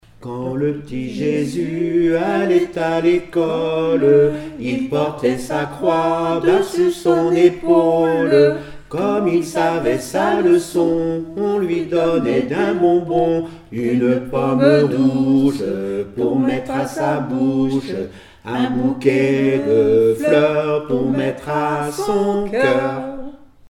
Mémoires et Patrimoines vivants - RaddO est une base de données d'archives iconographiques et sonores.
enfantine : berceuse
Comptines et formulettes enfantines
Pièce musicale inédite